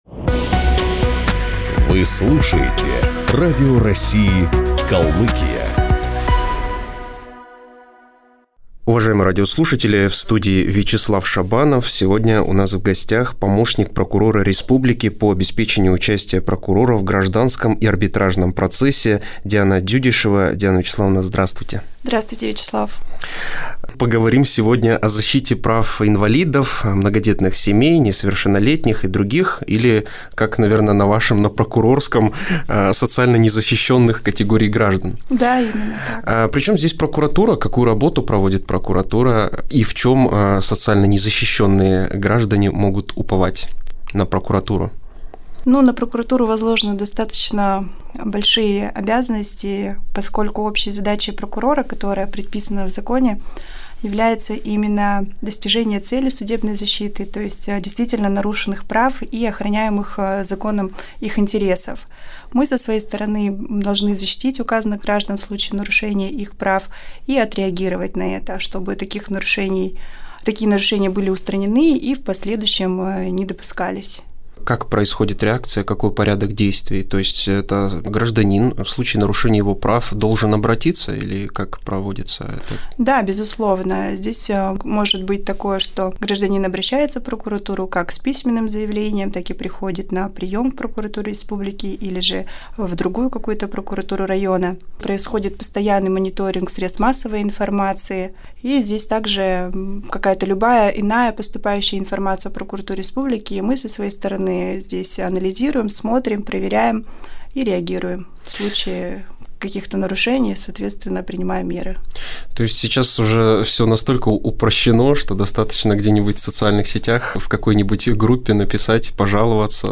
Распечатать Архив новостей 1 Аудио Интервью Скачать [ 16.5 Мб ] Прямая ссылка на материал Копировать Поделиться Вконтакте LiveJournal